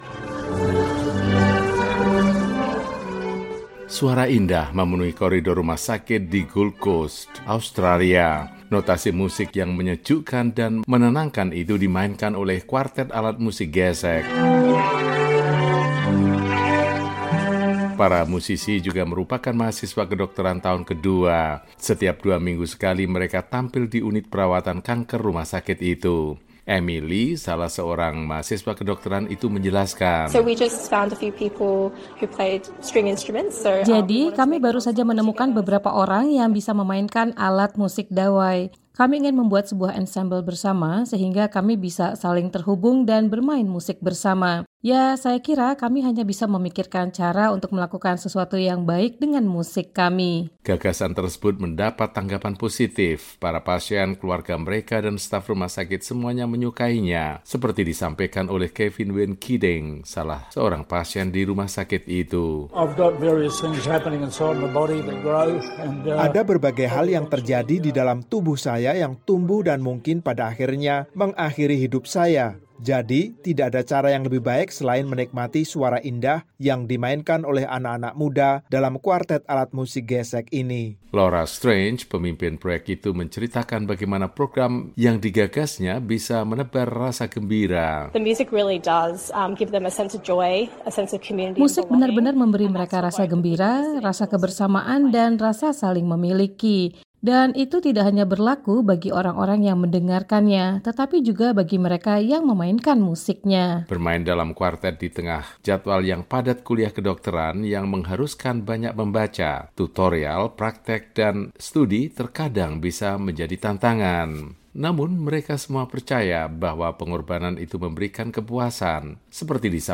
Mahasiswa Kedokteran Pentas Rutin Musik Klasik di RS
Para mahasiswa kedokteran menunjukkan bakat musik mereka kepada para pasien dan staf di sebuah rumah sakit Gold Coast, di Australia. Dua minggu sekali, mereka berkumpul dan tampil di unit perawatan kanker.